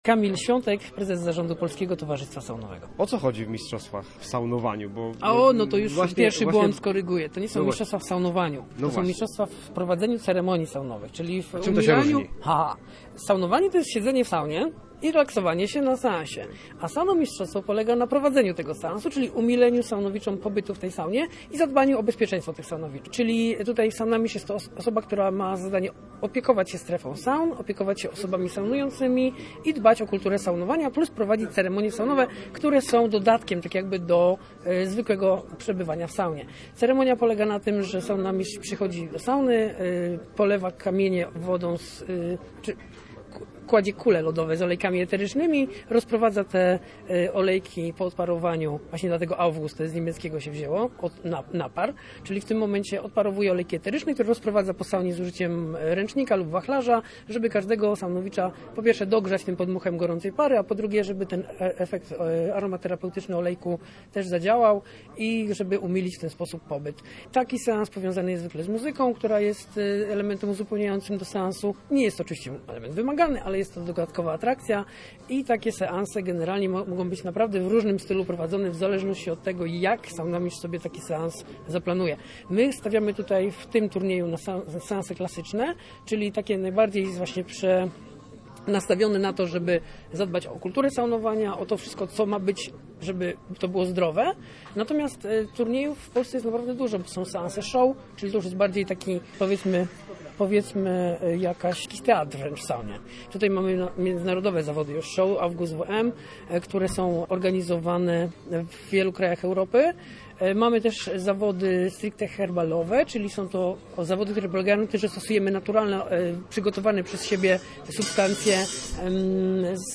Posłuchaj relacji reportera Radia Gdańsk: